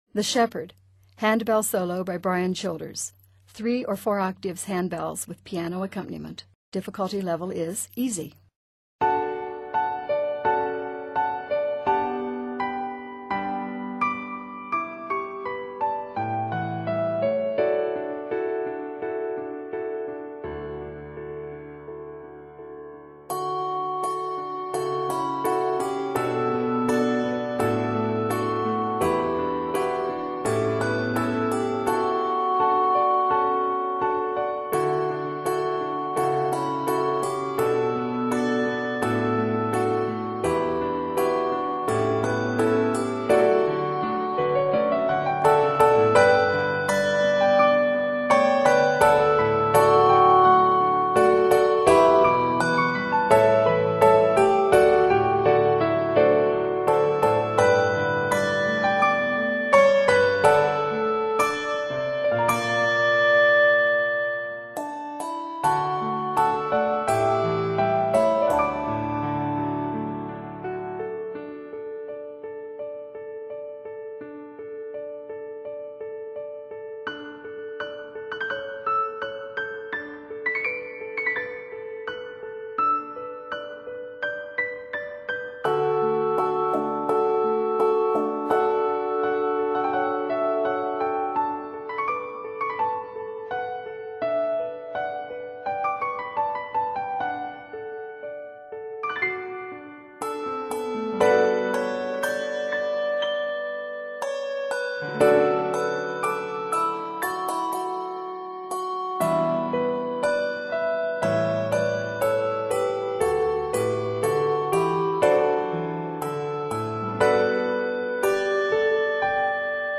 Solo Ringer
Hymn Tune
LV (Let Vibrate)